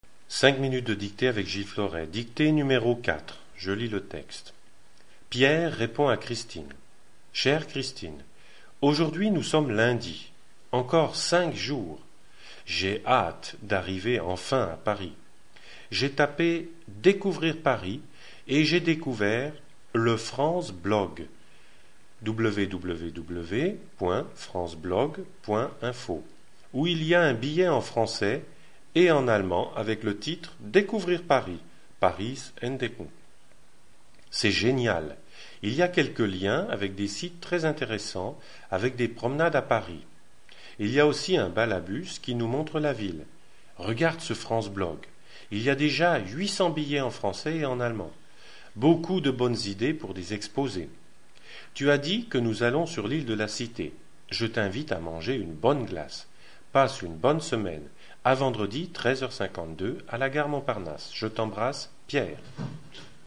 Cinq minutes de dictée
dictee-4-a.mp3